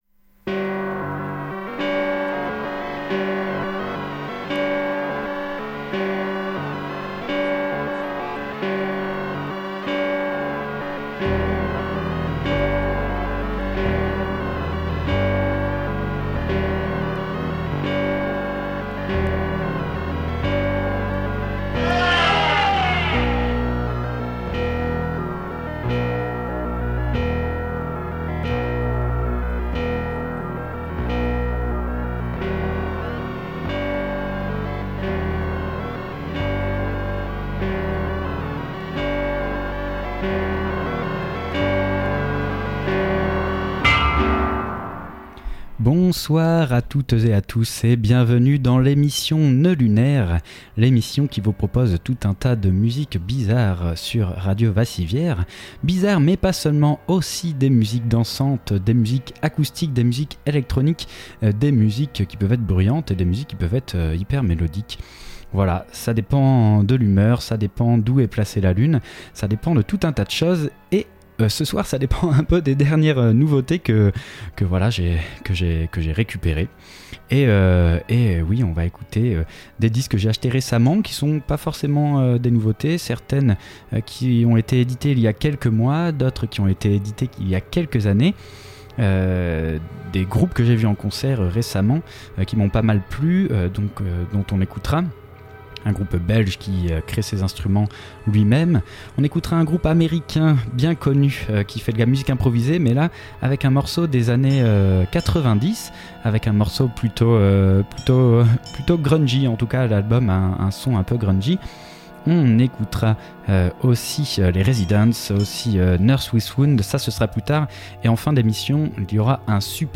groupe de rock industriel Américain formé en 1981.